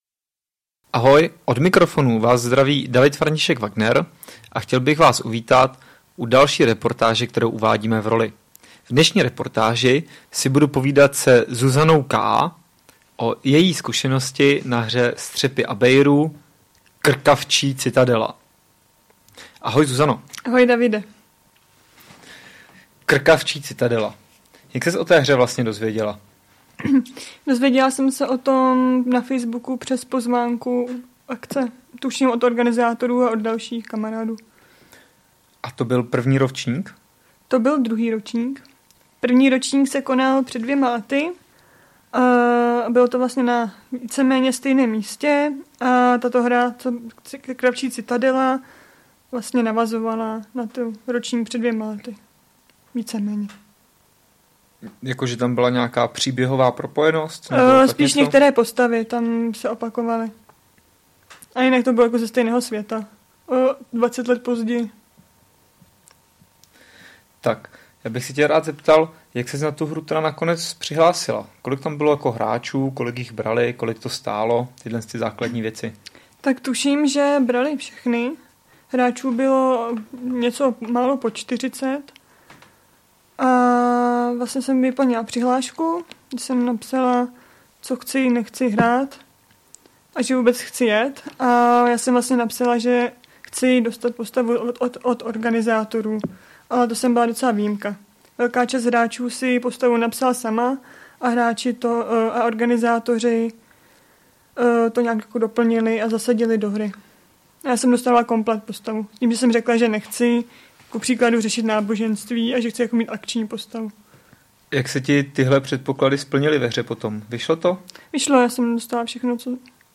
Než zahájíme druhou sérii, připravili jsme si pro vás speciální díl, ve kterém zrekapitulujeme náhodně vybrané hry letošního léta. Zvolili jsme formu reportáže, ústy účastníků se dozvíme něco o hrách Bezčasí, Todesspiel a Střepy Abeiru.
V první části promluví jedna z účastnic larpu Střepy Abeiru: Krkavčí citadela.